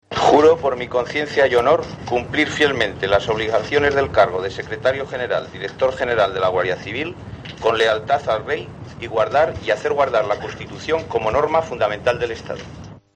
Luis Roldán jura el cargo como director general de la Guardia Civil